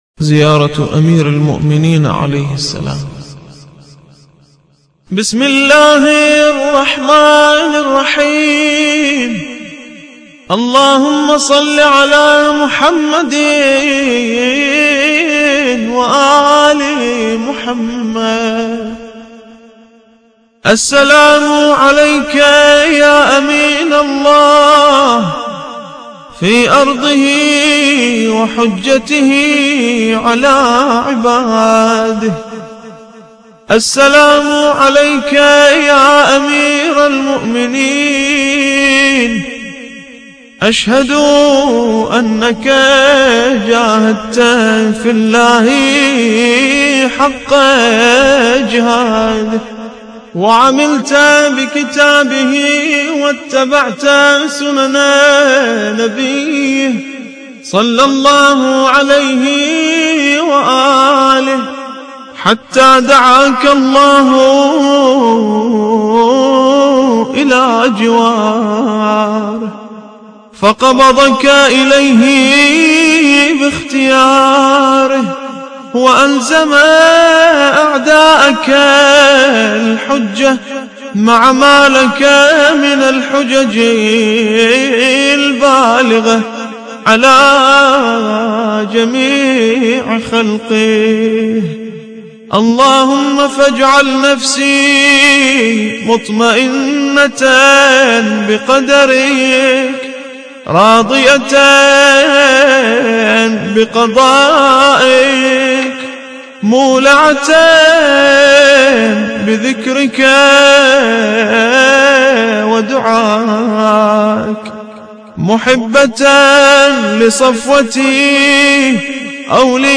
زيارة أمير المؤمنين عليه السلام – الرادود